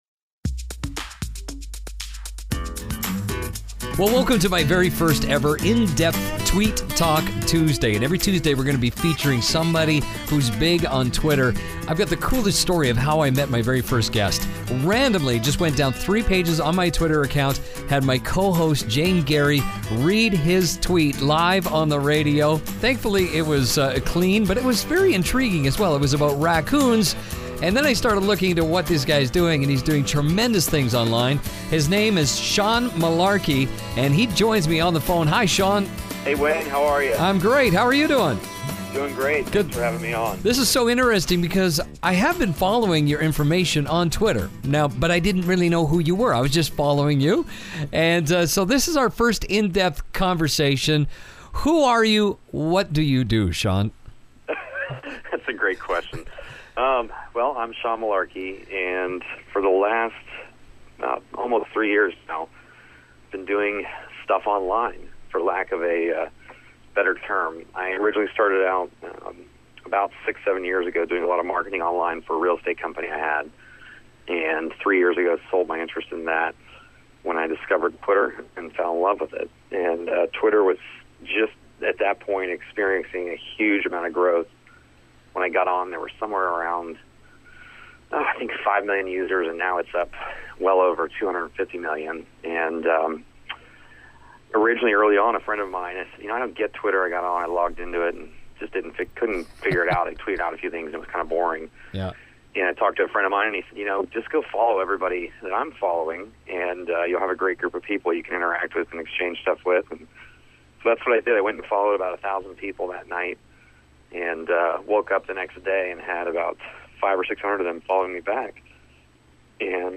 Today you are going to find out…it's my first in depth interview for Tweet Talk Tuesday.